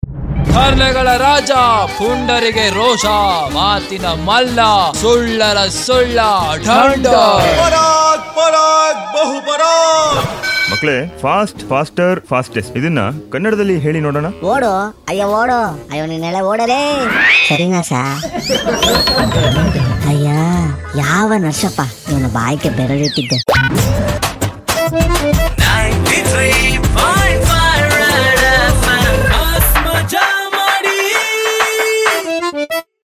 Most Loved Comedy Audio Clip That Makes YOU ROFL!!!